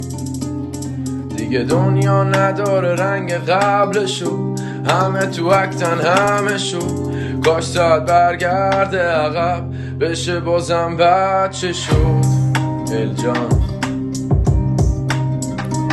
پاپ غمگین